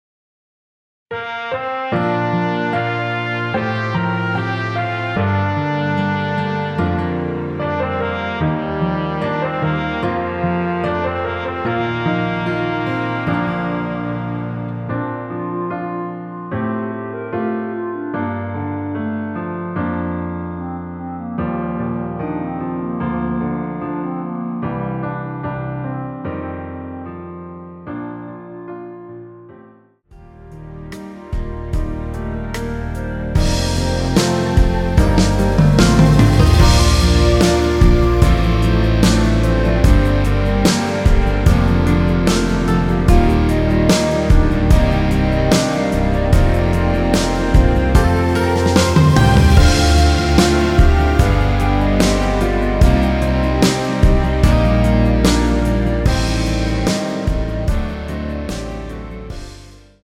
원키에서(-2)내린 멜로디 포함된 MR입니다.(미리듣기 확인)
Bb
앞부분30초, 뒷부분30초씩 편집해서 올려 드리고 있습니다.
중간에 음이 끈어지고 다시 나오는 이유는